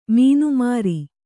♪ mīnu māri